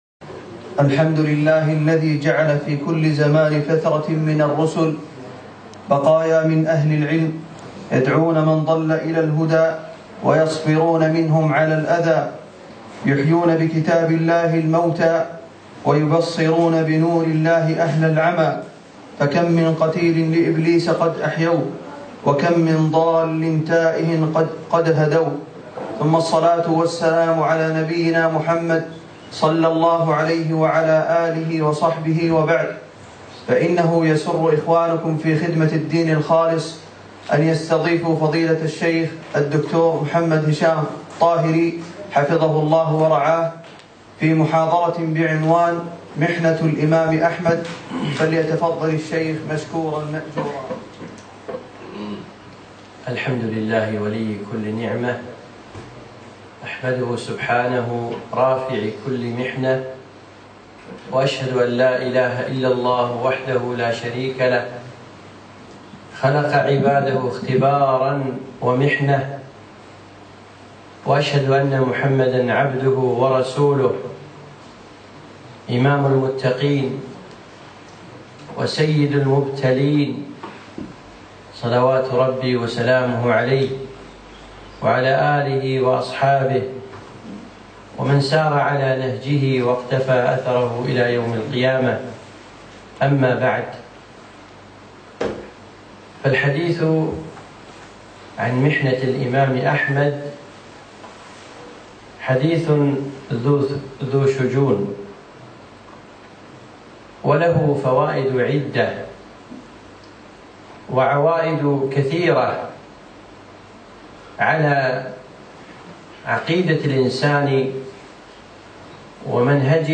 محاضرة محنة الإمام أحمد - في دولة قطر